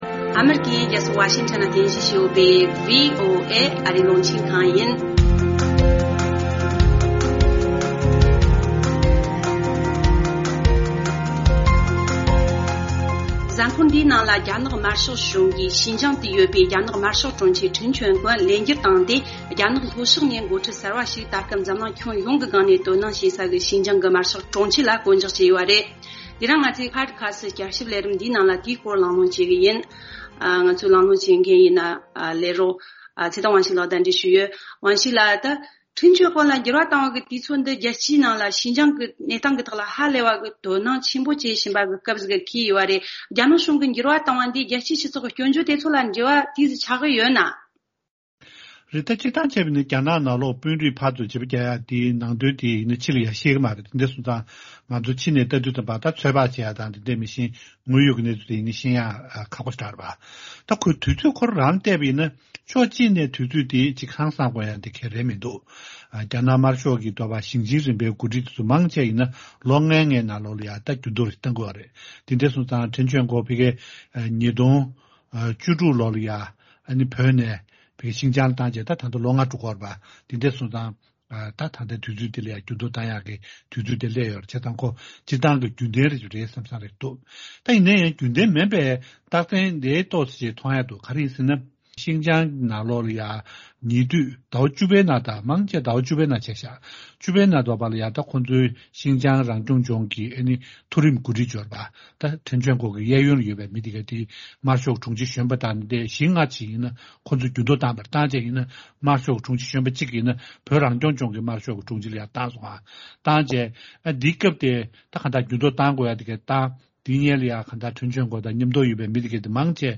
དེ་རིང་ང་ཚོའི་ཕོ་ཌི་ཁ་སི་བསྐྱར་ཞིབ་ལས་རིམ་ལ་དེའི་སྐོར་བགྲོ་གླེང་ཞུས་ཡོད།།